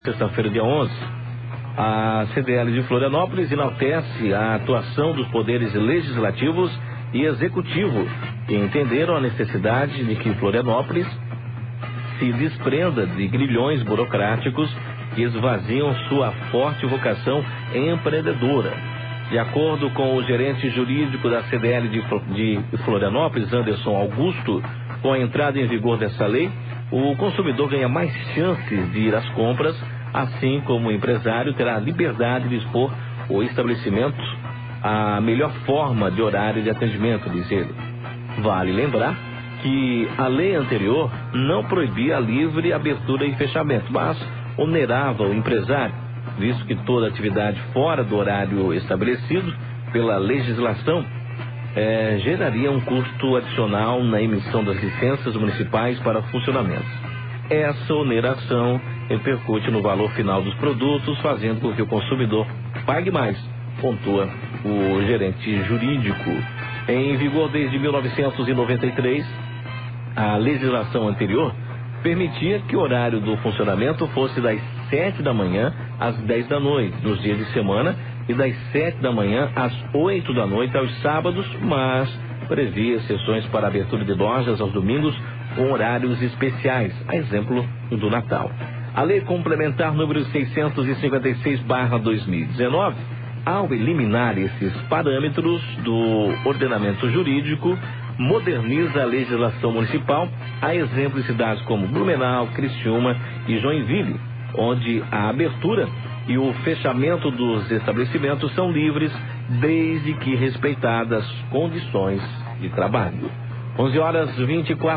CDL de Florianópolis - Rádio